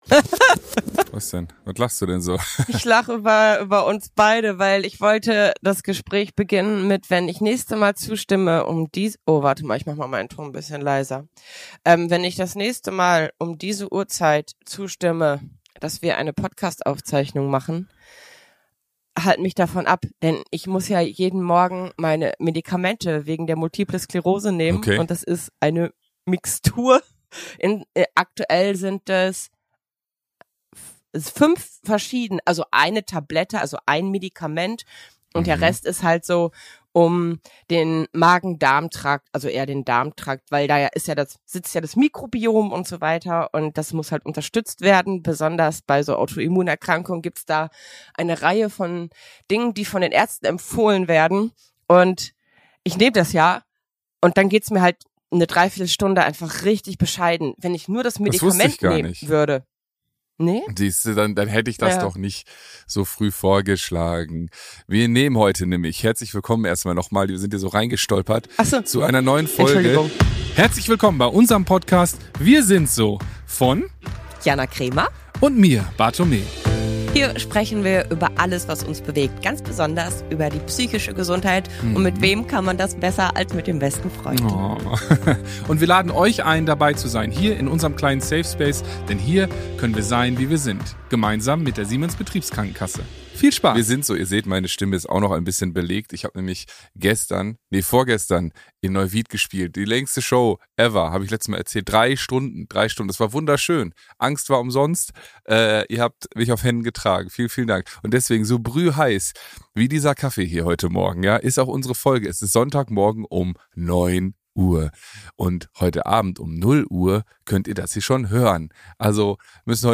Darüber sprechen die beiden miteinander und auch mit ihren spannenden Gästen.